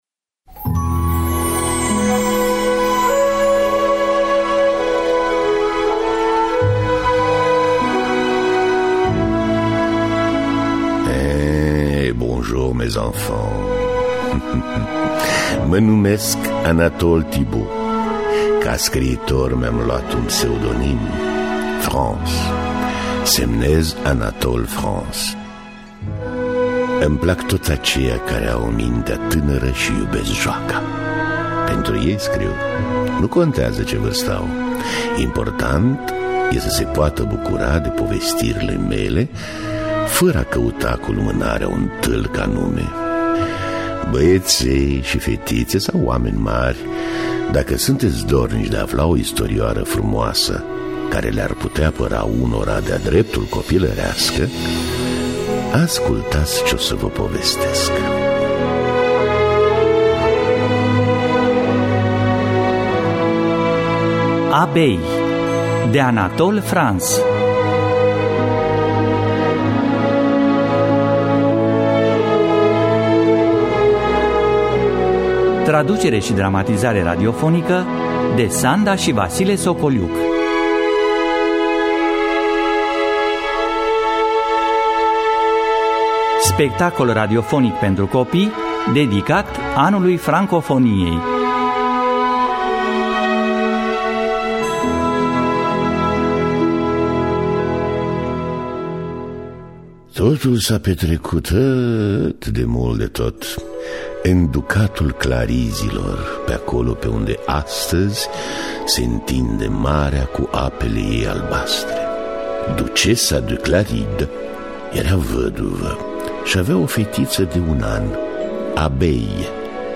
Abeille de Anatole France – Teatru Radiofonic Online